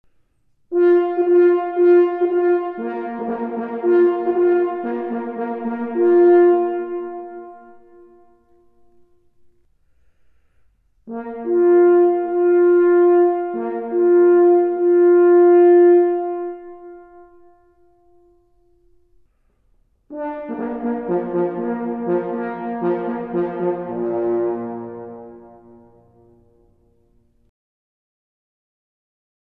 Informace pro všechny příznivce a zájemce o myslivecké troubení a loveckou hudbu
06_Signaly_uzivane_v_leci.mp3